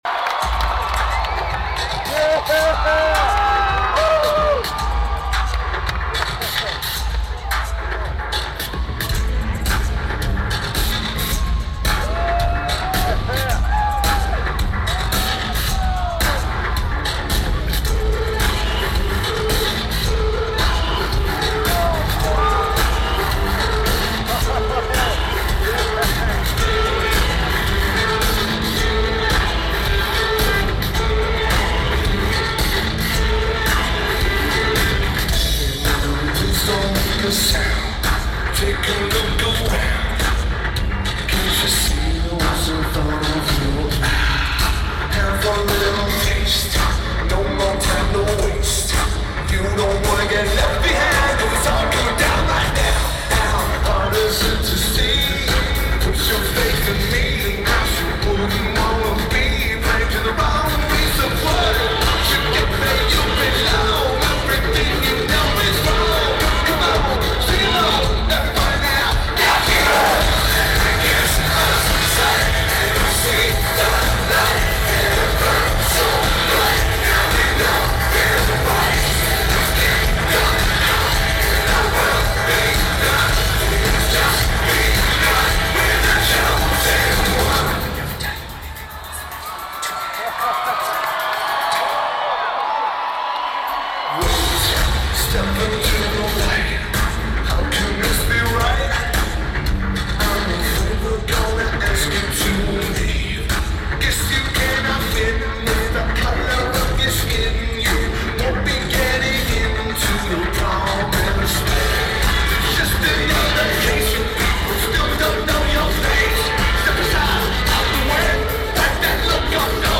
Ford Center
Lineage: Audio - AUD (Sony MZ-N707 + Unknown Mic)